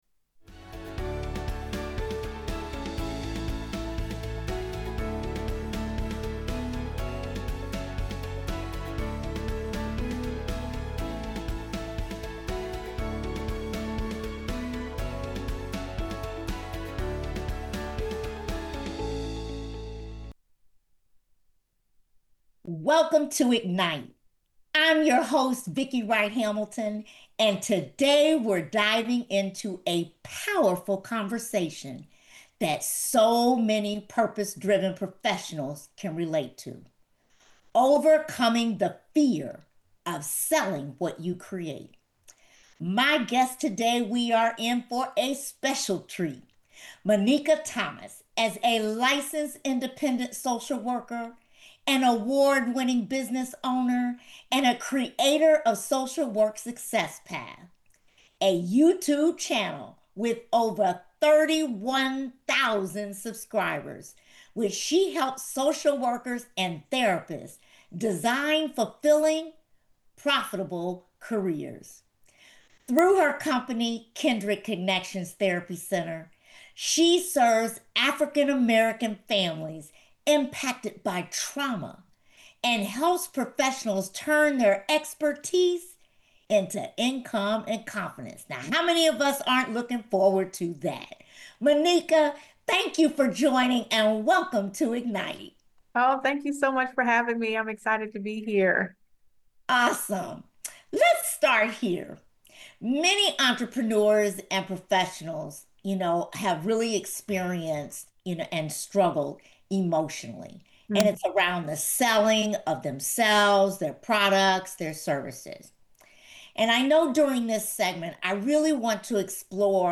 Join us as we unpack how to transform fear into fuel, reframe failure as growth, and build the confidence needed to pursue your vision relentlessly. Our guests share raw, powerful stories about confronting internal resistance and breaking through limiting beliefs to achieve lasting fulfillment and impact.